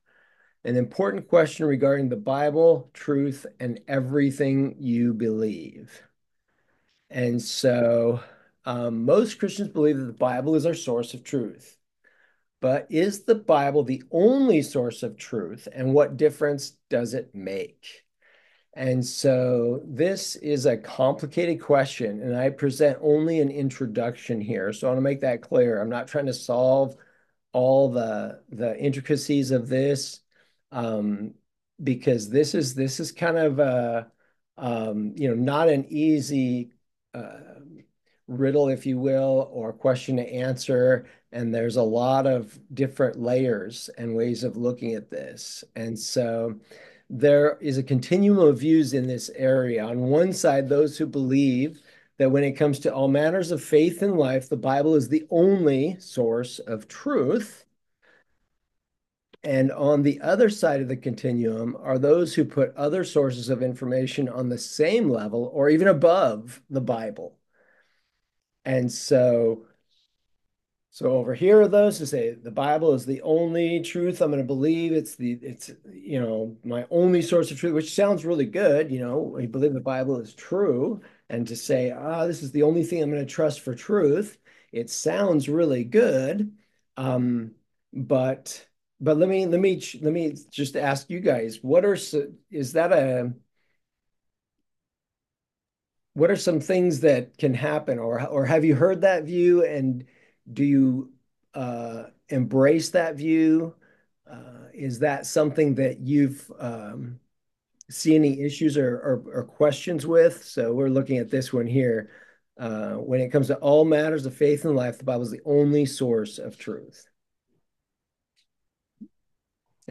Discussion on the "Source of Truth" and Views About Integration